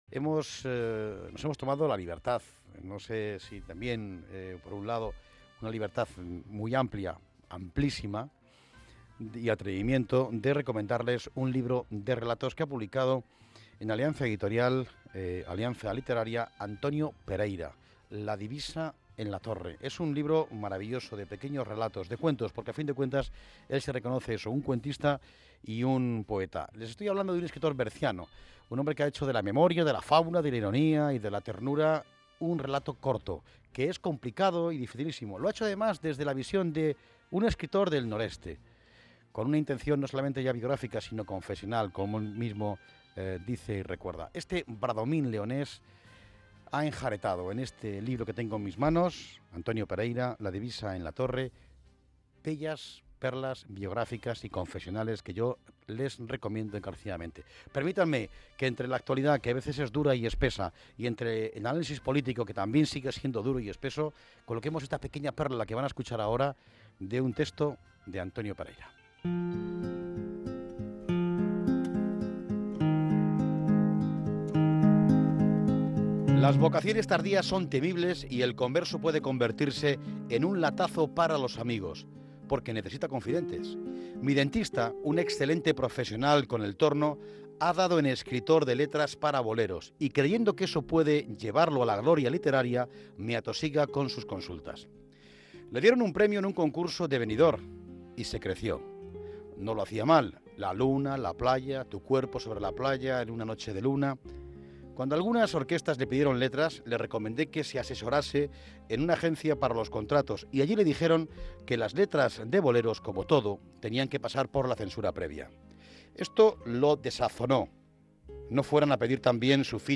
Lectura de un extracto de un cuento de la obra «La divisa en la Torre»